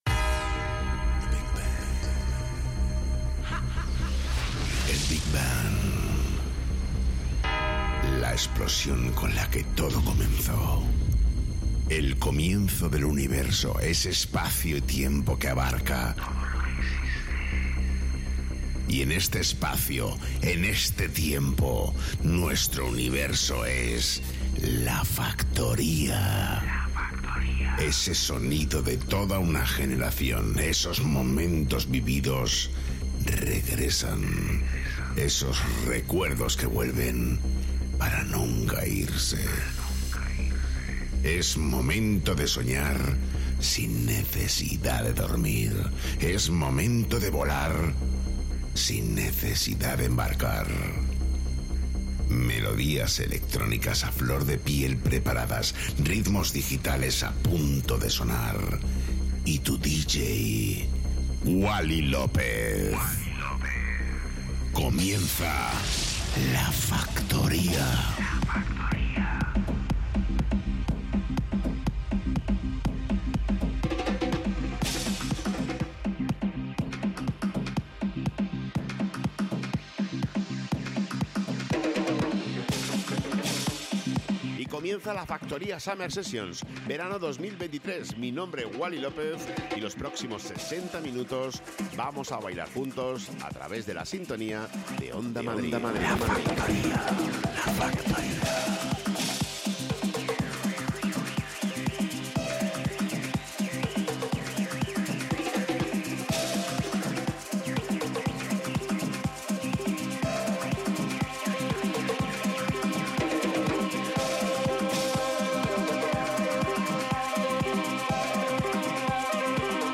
el DJ más internacional de Madrid